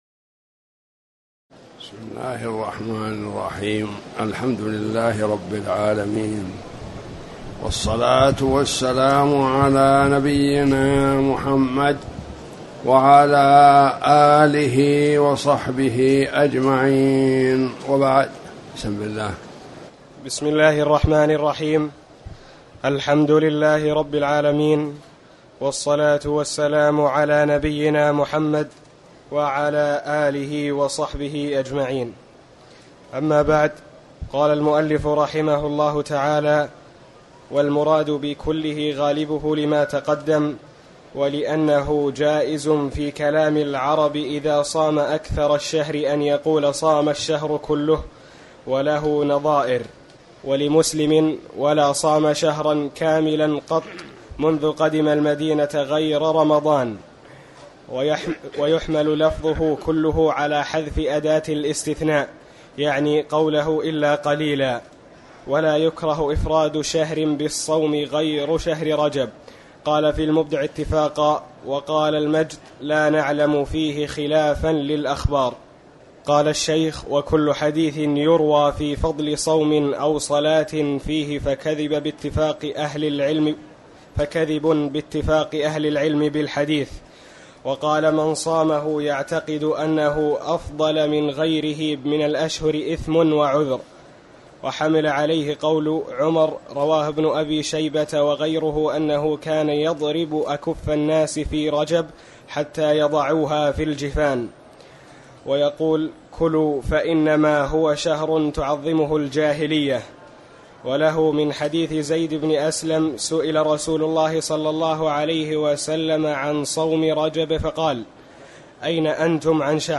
تاريخ النشر ٢٩ ذو القعدة ١٤٣٩ هـ المكان: المسجد الحرام الشيخ